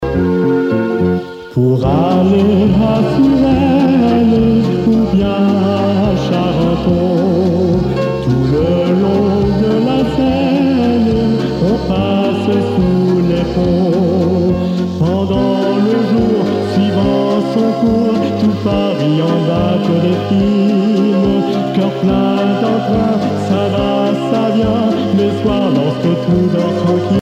valse
Pièce musicale éditée